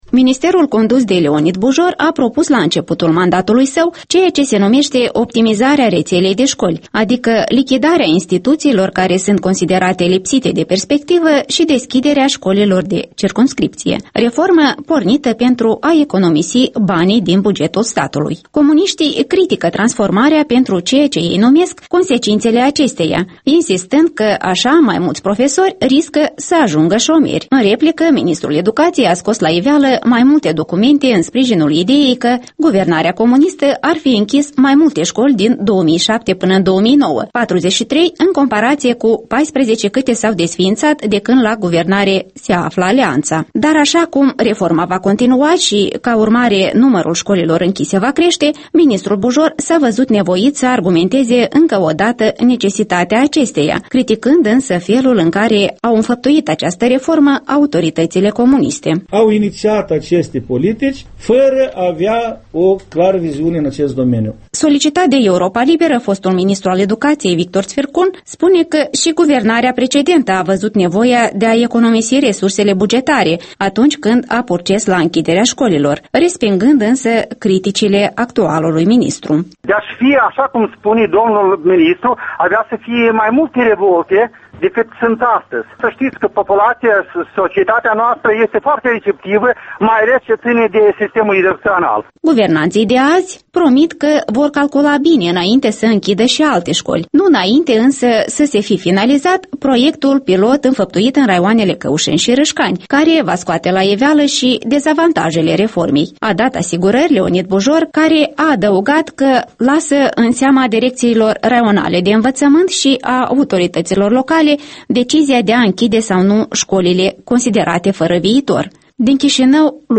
Conferință de presă a ministrului educației